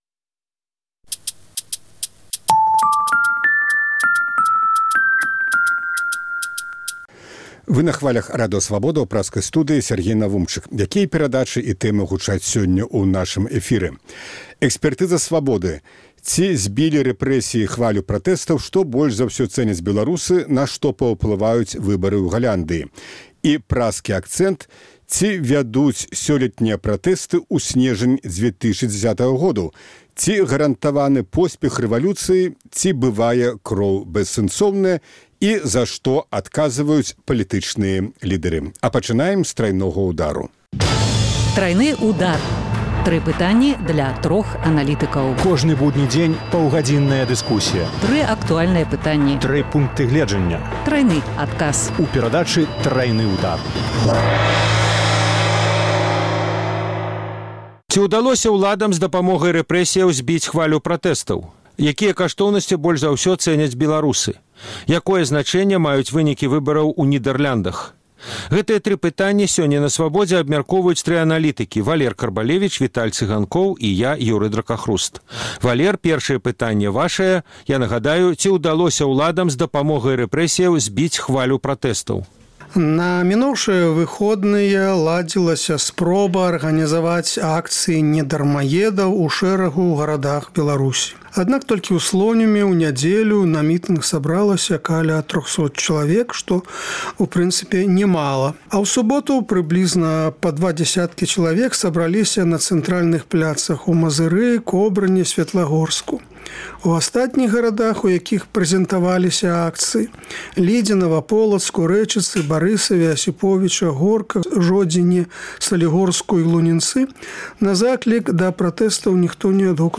Тры пытаньні для трох аналітыкаў. Ці ўдалося ўладам з дапамогай перасьледу грамадзкіх лідэраў зьбіць хвалю пратэстаў? Пра што сьведчаць вынікі параўнальнага дасьледаваньня жыцьцёвых каштоўнасьцяў у Беларусі, Малдове і Ўкраіне?